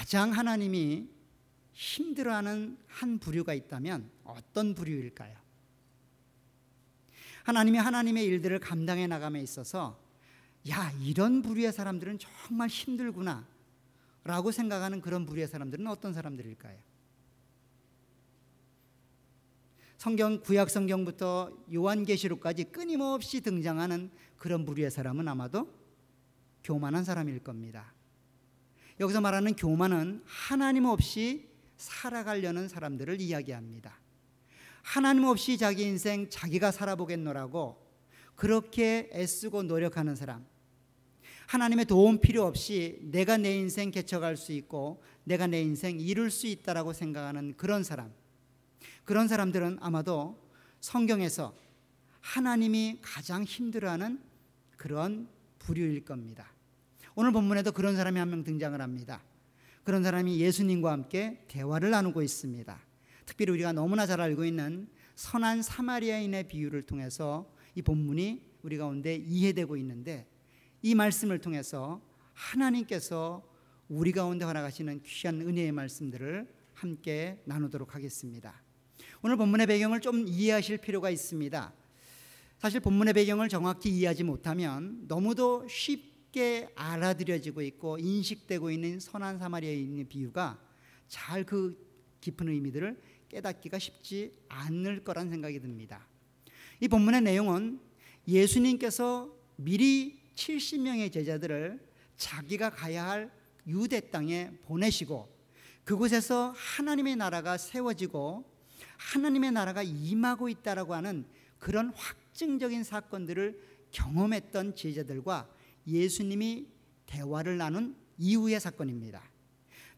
Series: 주일예배.Sunday